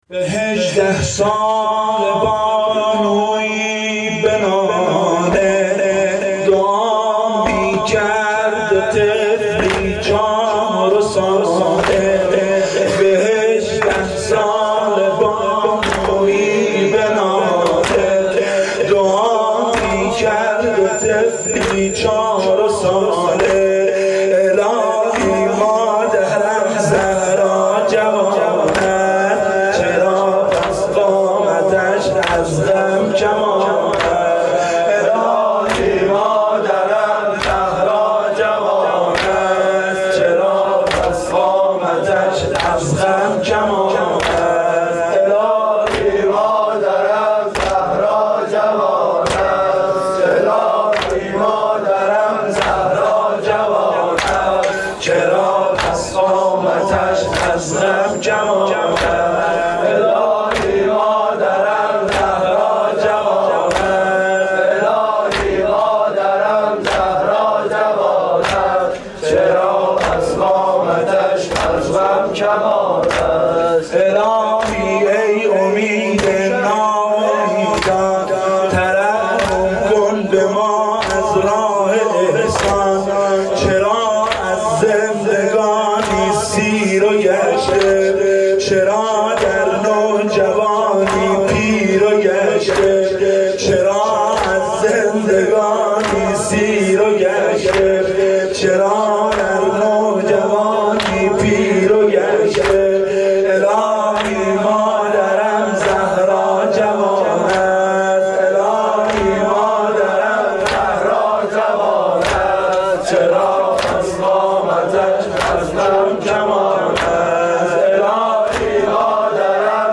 دم سینه زنی شب سوم فاطمیه 1393
• شب سوم فاطمیه 1393, حسینیه
مداحی فاطمیه